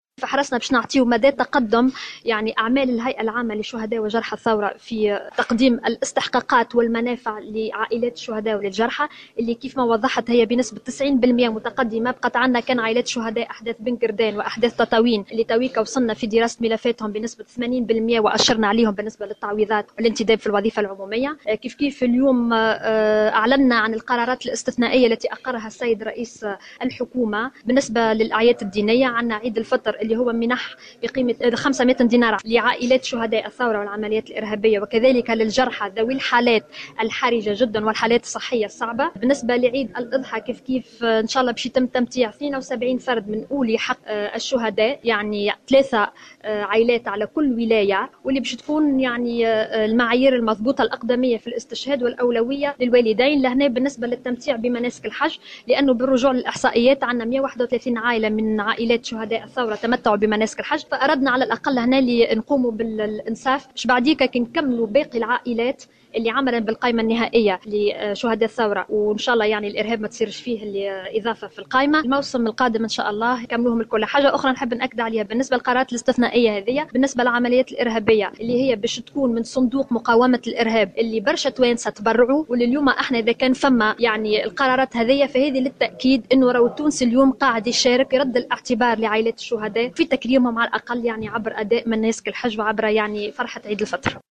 على هامش ندوة صحفية عقدت اليوم الجمعة 1 جويلية 2016 بمقر رئاسة الحكومة بالقصبة